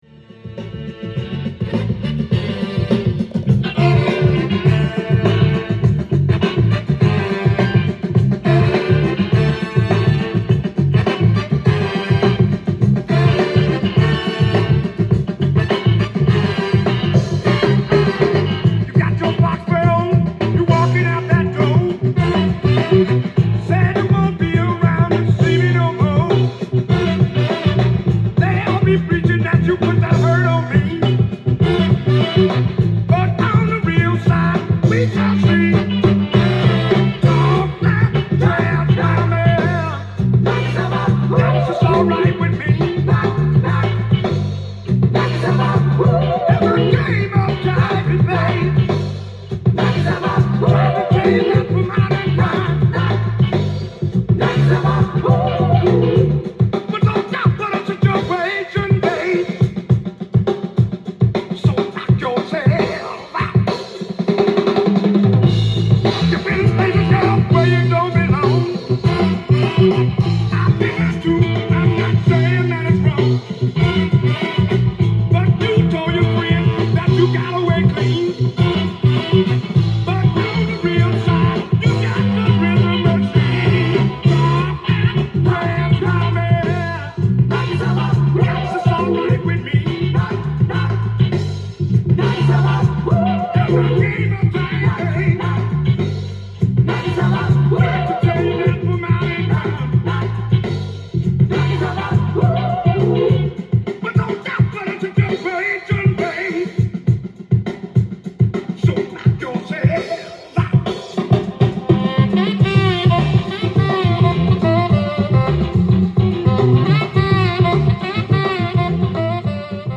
ジャンル：FUNK
店頭で録音した音源の為、多少の外部音や音質の悪さはございますが、サンプルとしてご視聴ください。
全曲、分厚いブラス/ホーンが鉄壁を作るベイエリアファンククラシック！